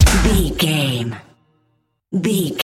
Aeolian/Minor
drum machine
synthesiser
90s
Eurodance